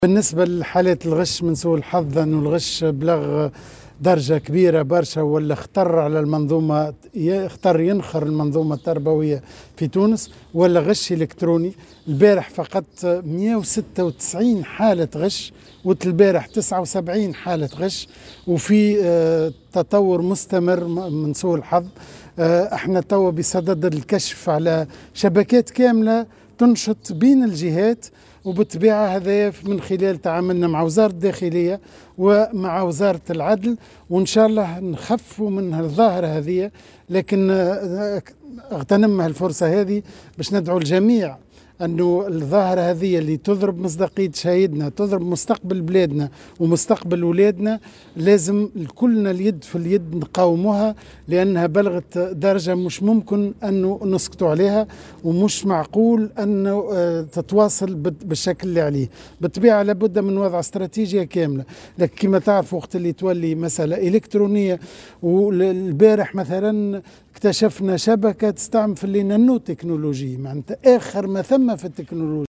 وأضاف في تصريح لمراسل "الجوهرة أف أم" على هامش زيارته لمركز الاختبارات الكتابية لامتحان البكالوريا لأطفال القمر بالمدرسة الإعدادية" التحدي" بخنيس في ولاية المنستير، أنه تم يوم أمس الكشف عن شبكة للغش باستعمال أحدث التقنيات.